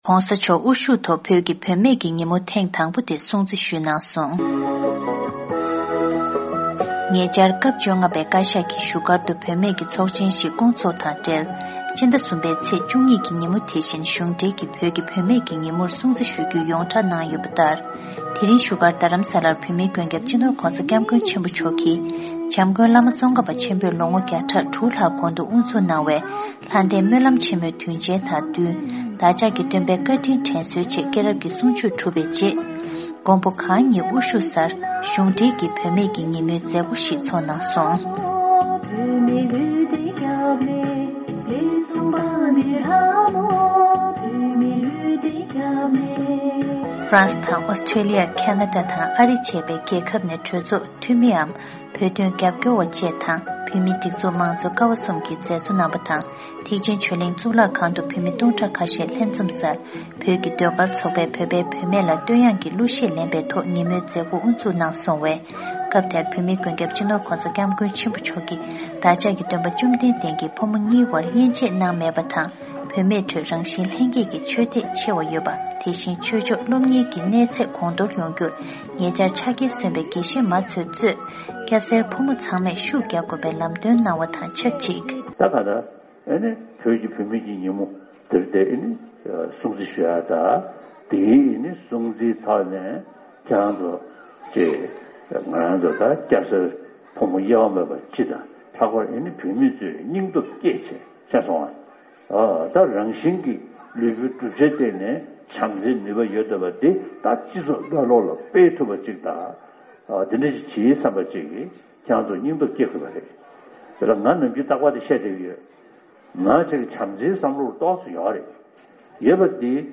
Dalai Lama Presides Over First Tibetan Women's Day  Following the Jataka tales teaching, the Dalai Lama presided over the first official 'Tibetan Women's Day' marked in his exile home of Dharamsala, North India on March 12, 2017.